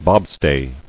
[bob·stay]